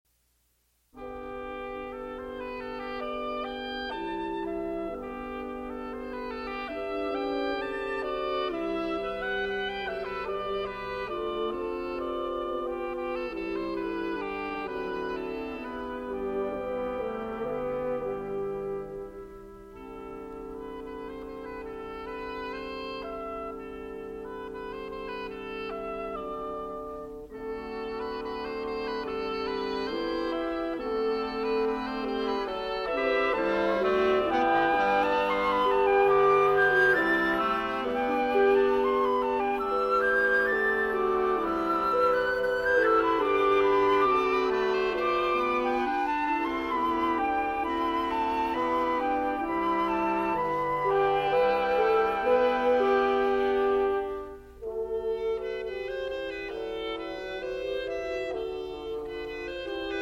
William Kincaid - Flute
Oboe
Clarinet
French Horn
Bassoon